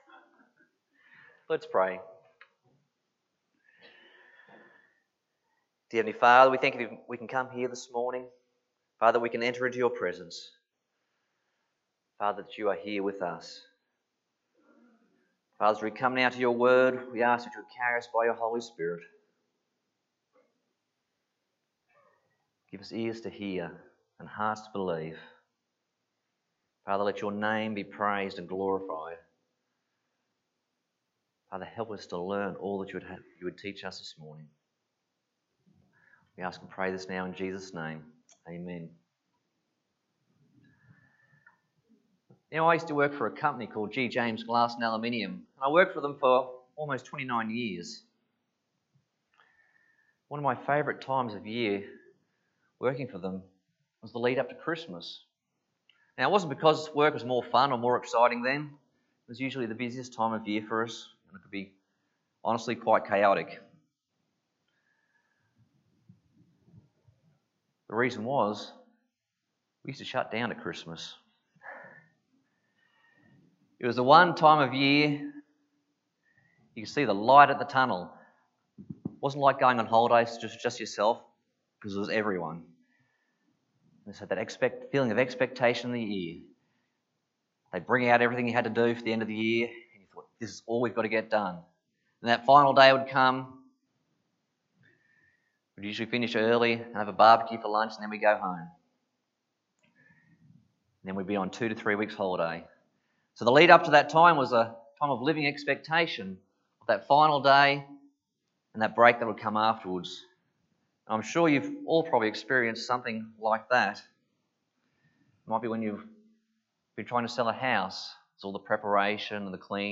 Passage: 1 Peter 4:7-11 Service Type: Sunday Morning